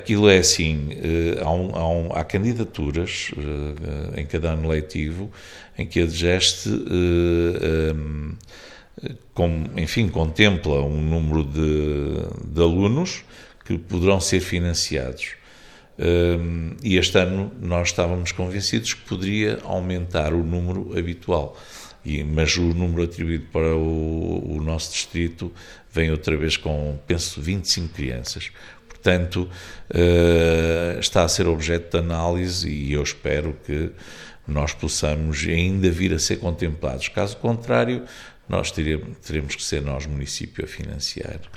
O presidente da câmara, esclarece que neste momento falta uma licença da DGEsTE – Direção Geral dos Estabelecimentos Escolares:
reuniao-de-camara-2.mp3